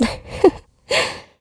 Ripine-Vox_Happy1_kr.wav